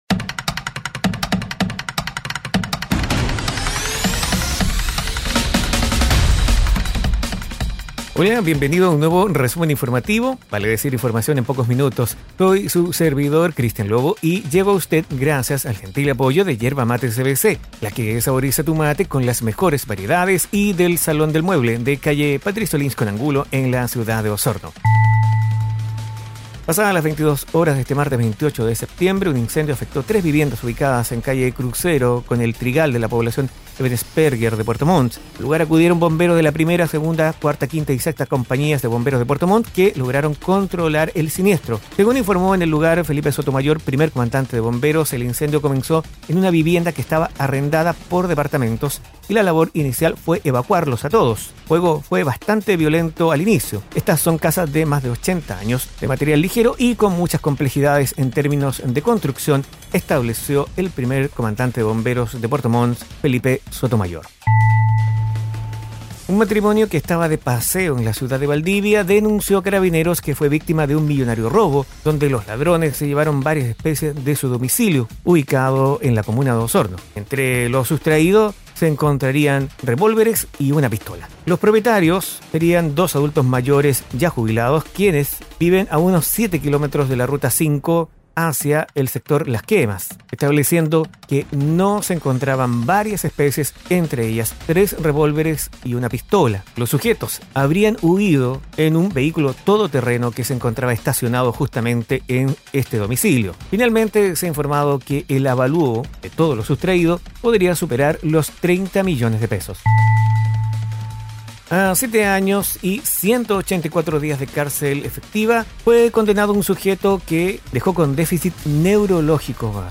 Informaciones y noticias enfocadas en la Región de Los Lagos. Difundido en radios asociadas.